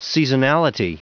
Prononciation du mot seasonality en anglais (fichier audio)
Prononciation du mot : seasonality